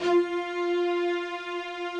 Updated string samples
strings_053.wav